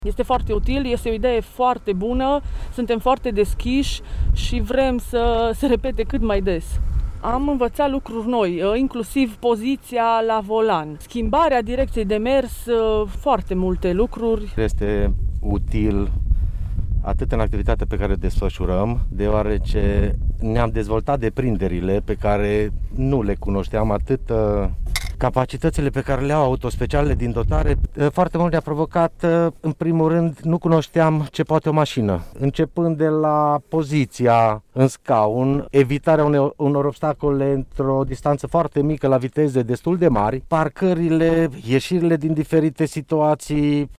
Aceștia sunt încântați de activitate și spun că au învățat lucruri noi: